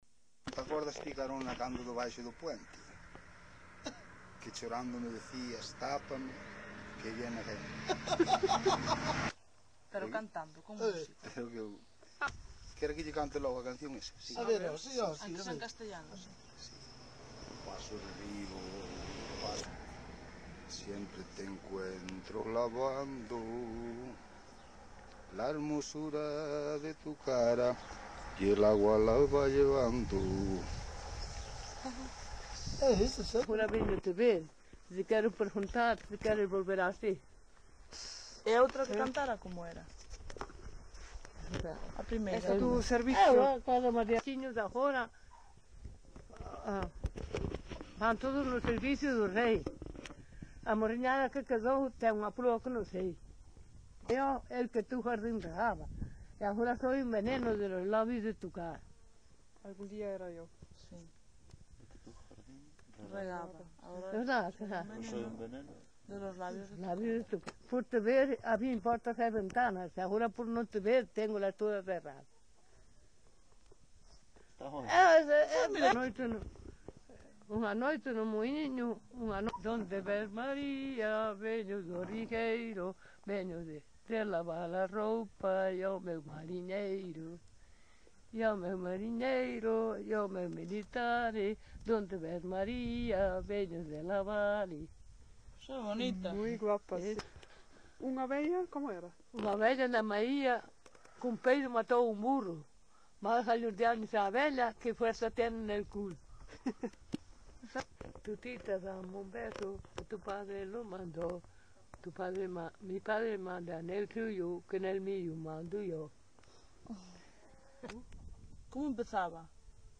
Coplas
Tipo de rexistro: Musical
Soporte orixinal: Casete
Datos musicais Refrán
Instrumentación: Voz
Instrumentos: Voz masculina, Voz feminina